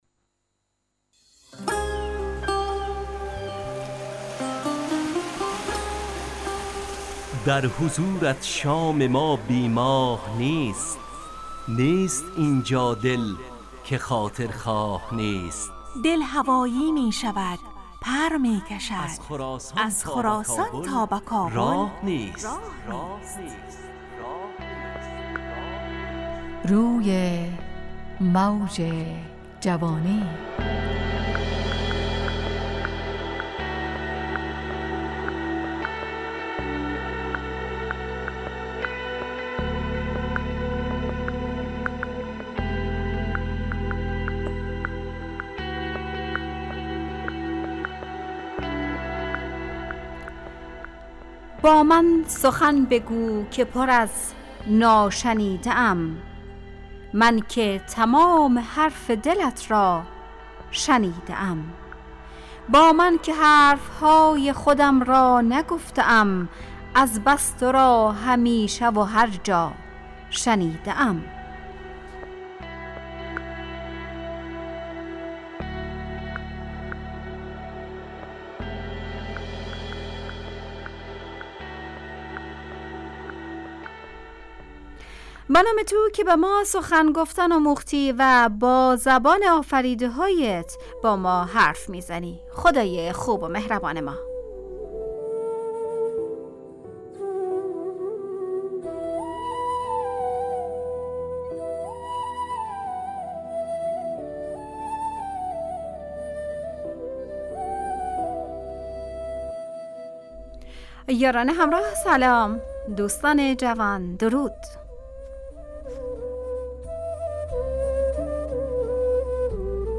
برنامه شادو عصرانه رادیودری. از شنبه تا پنجشنبه ازساعت 17 الی 17:55 به وقت افغانستان، طرح موضوعات روز، وآگاهی دهی برای جوانان، و.....بخشهای روزانه جوان پسند. همراه با ترانه و موسیقی مدت برنامه 55 دقیقه .